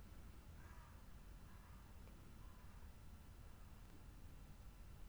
The only unadulterated file is my Room Tone, with no noise reduction or anything, which is below: